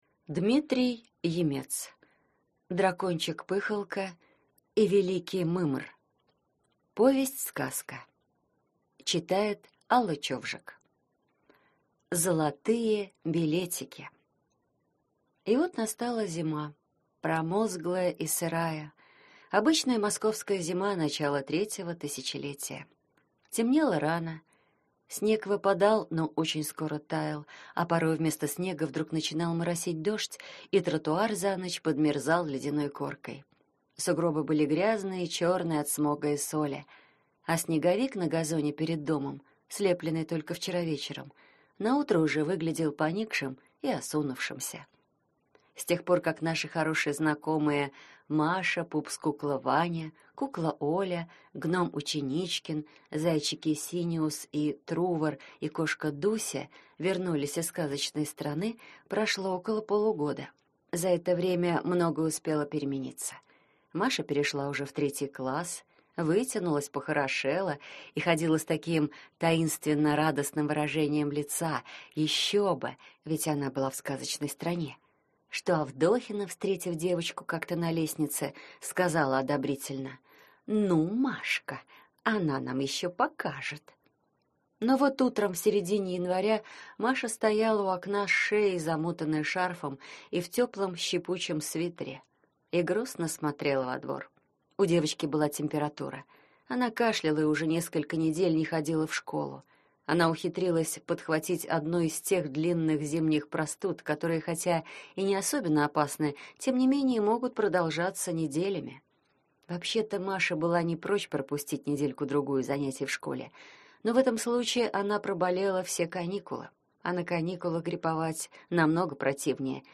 Аудиокнига Дракончик Пыхалка и Великий Мымр | Библиотека аудиокниг